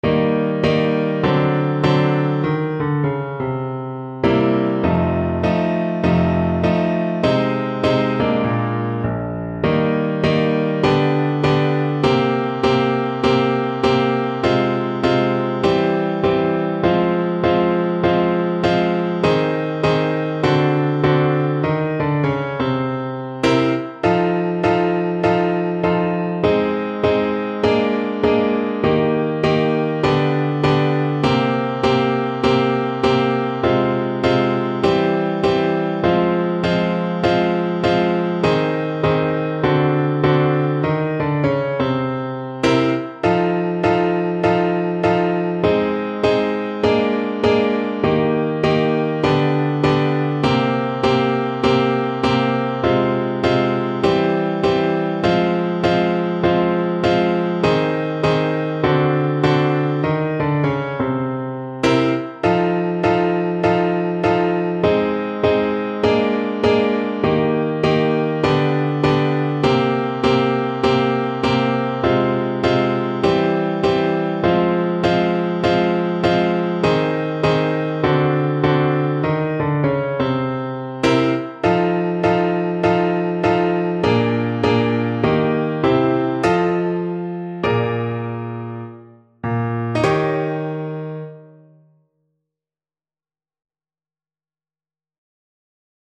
Moderato
Pop (View more Pop Tuba Music)